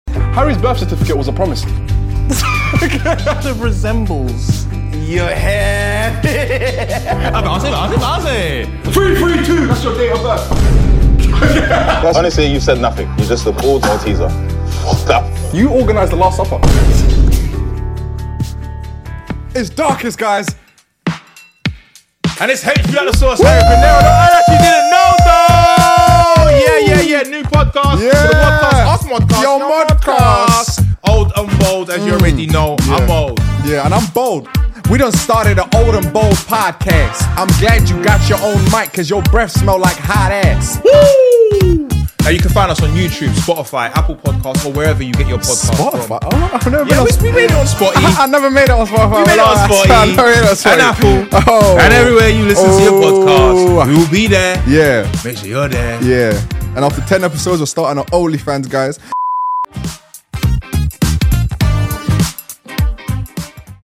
The brand new comedy podcast hosted by the two oldest and baldest men on YouTube... Darkest Man and Harry Pinero!